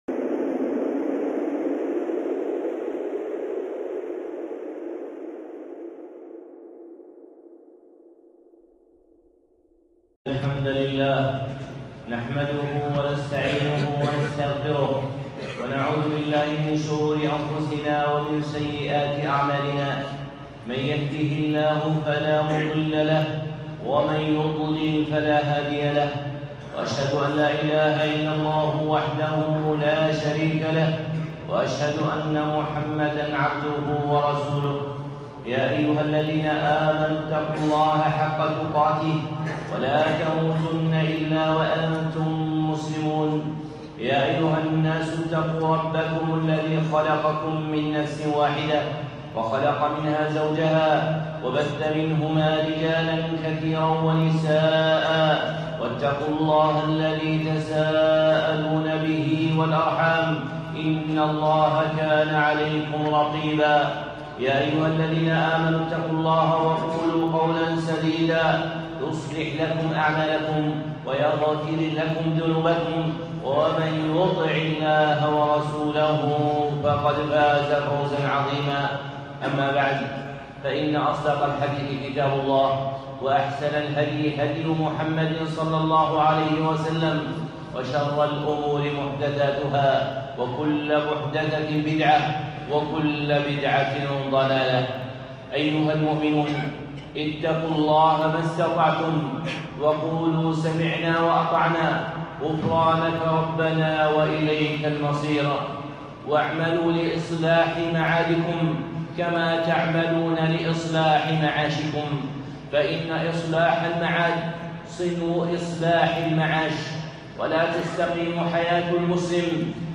خطبة (إصلاح المعاد)